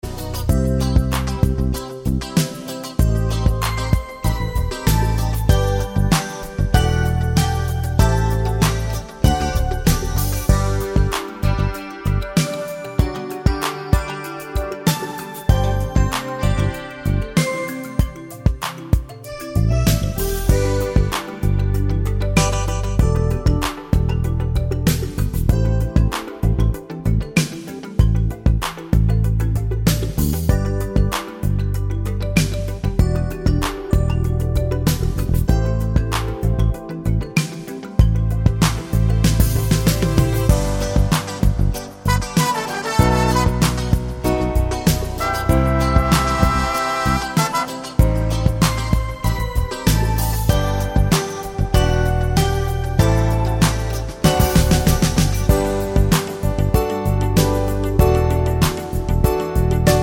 Medley Pop (1990s)